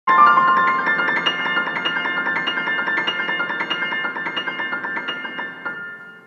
Classical Piano